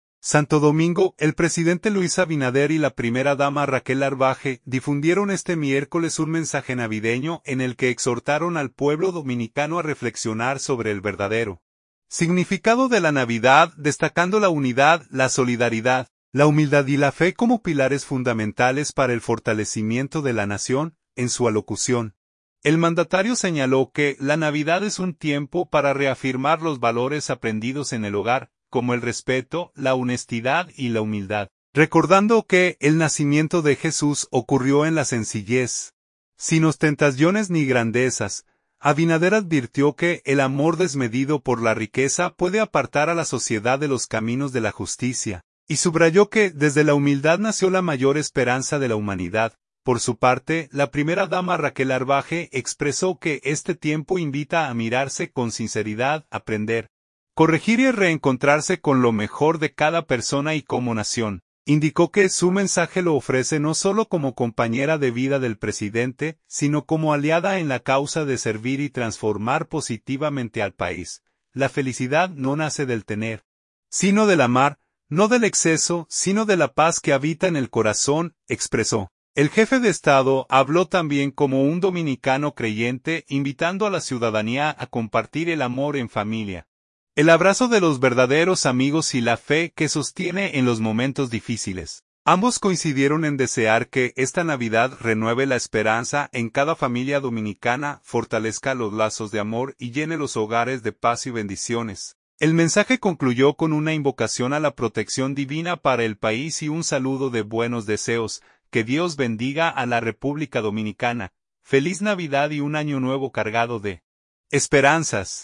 Santo Domingo.– El presidente Luis Abinader y la primera dama Raquel Arbaje difundieron este miércoles un mensaje navideño en el que exhortaron al pueblo dominicano a reflexionar sobre el verdadero significado de la Navidad, destacando la unidad, la solidaridad, la humildad y la fe como pilares fundamentales para el fortalecimiento de la nación.